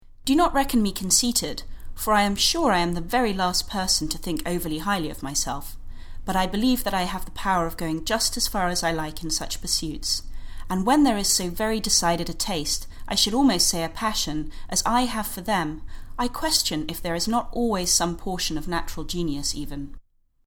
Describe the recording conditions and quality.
In Ada's Shop passersby are encouraged to interact with the window installation both physically and via mobile phone, activating motorized components, displays, lights, and sounds.